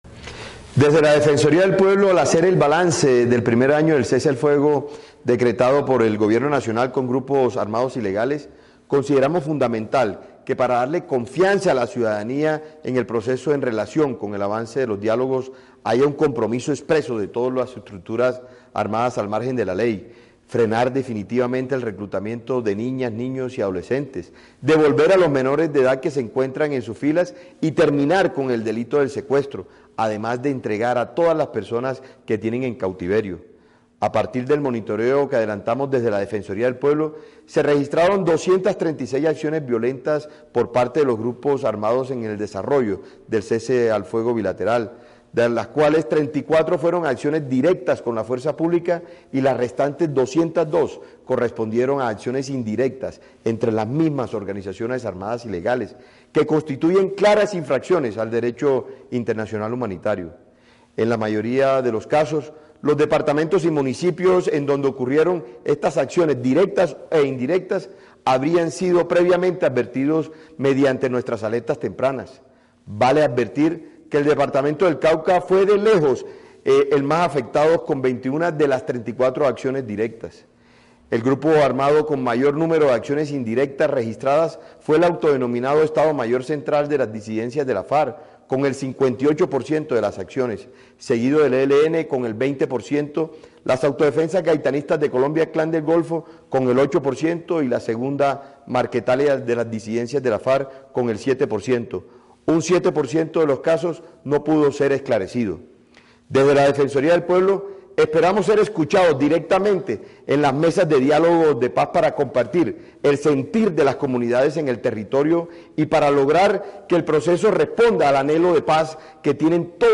Escuche el pronunciamiento del Defensor del Pueblo.